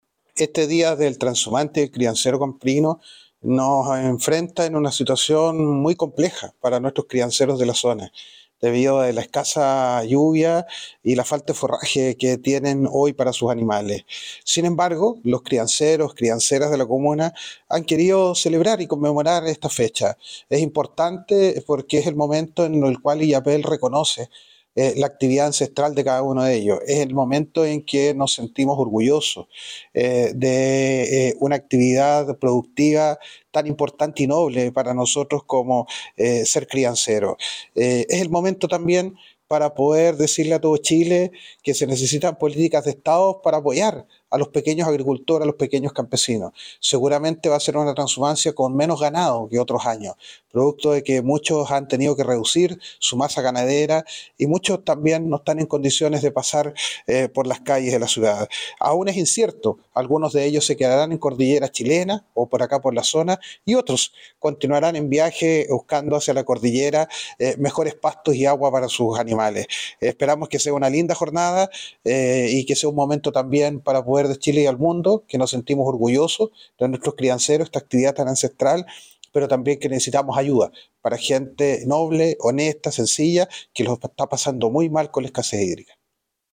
Pese a todo, esperamos que sea una linda jornada”, manifestó el alcalde de Illapel, Denis Cortés Aguilera.
Cuna-Alcalde-Denis-Cortes-Aguilera-Trashumacia.mp3